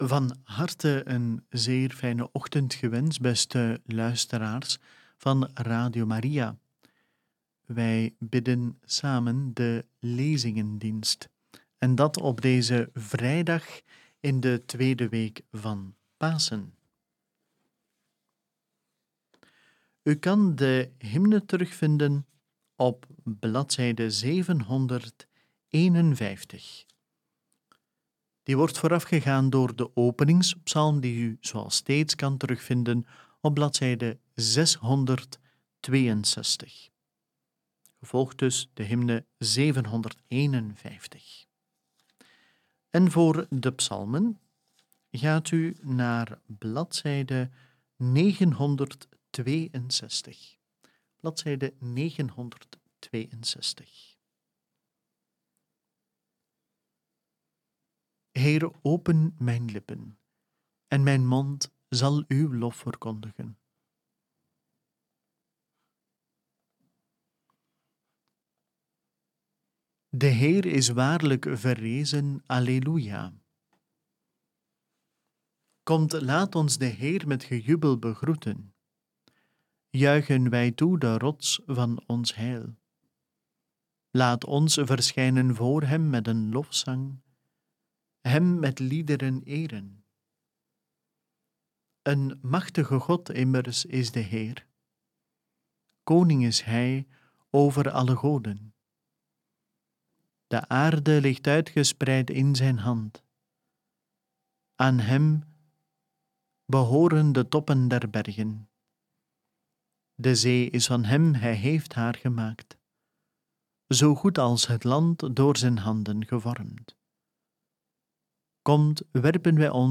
Lezingendienst